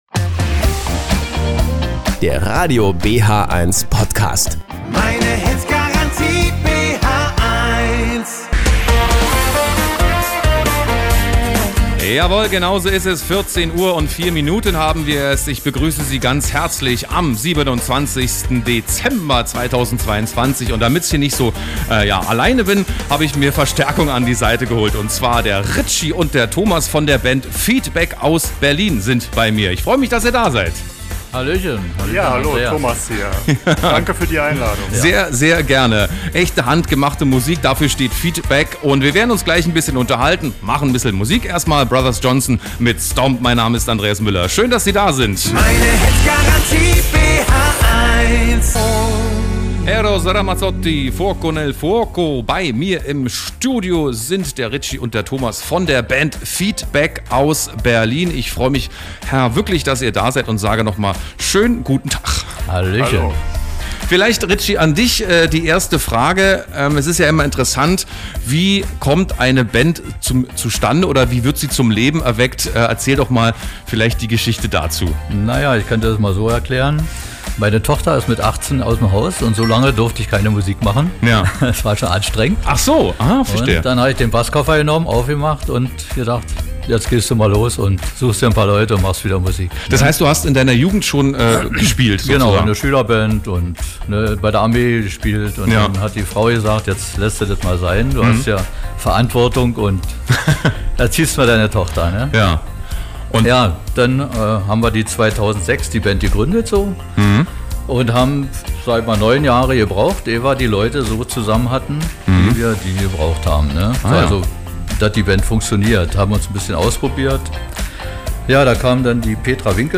Die Partyband aus Berlin im Gespräch